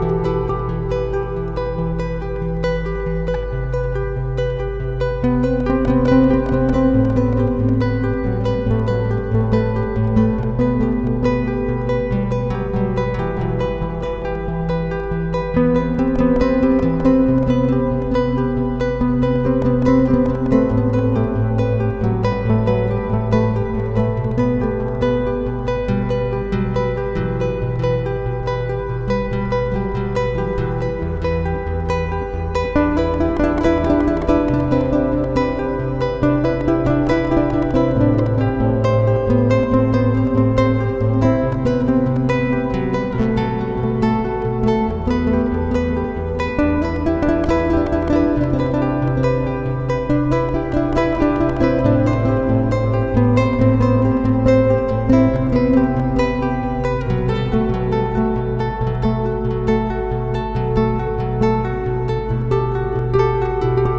Here I’ve given it a flamenco-like twist.